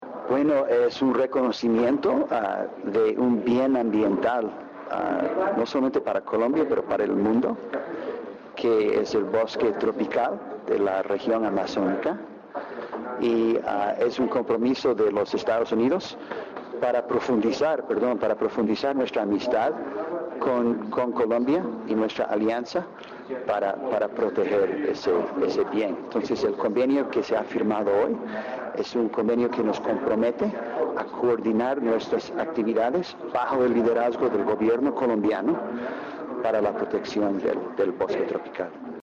Declaraciones del Ministro de Ambiente y Desarrollo Sostenible, Gabriel Vallejo López